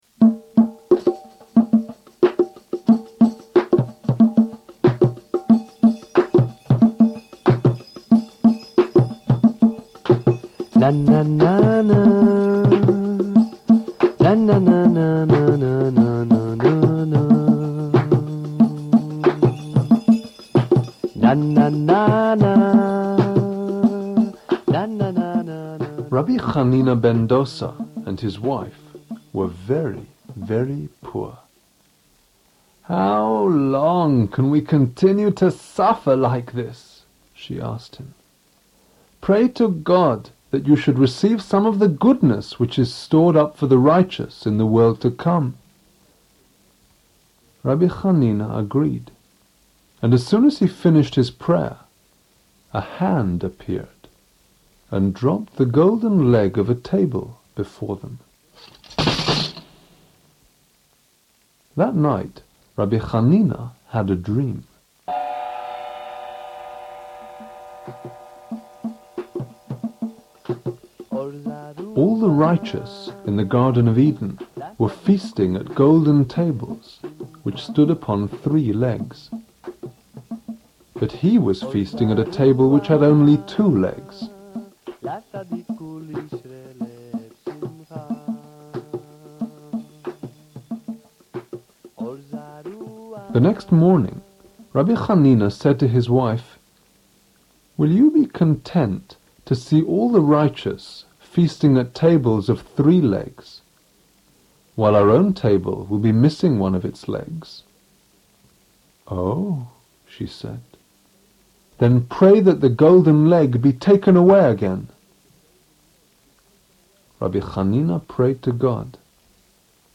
Here you can listen to Chassidic Meditation Melodies and stories of the Torah sages with music and sound effects, or download audio MP3s of classes on Prophecy & Wisdom (Hebrew Bible), Principles of Torah Law (Mishnah), The Laws of Shabbat and The Kabbalah Code of the Torah.
Stories of our Sages